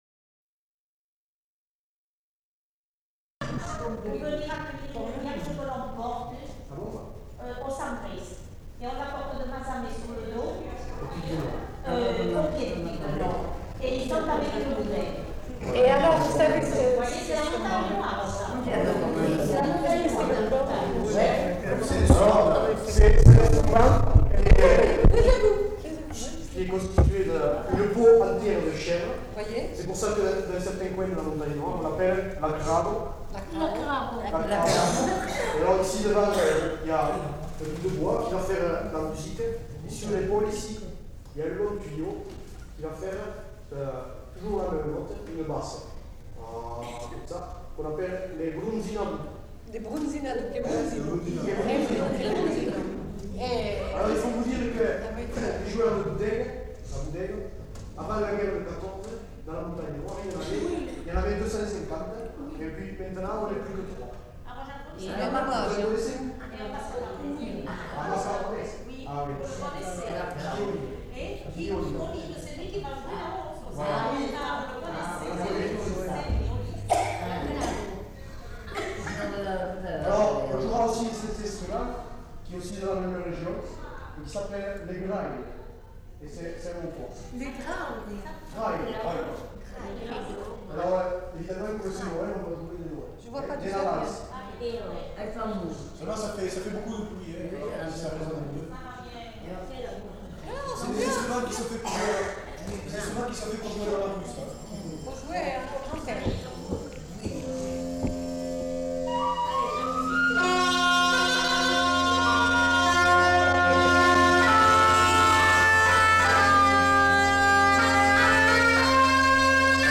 Présentation d'instruments de musique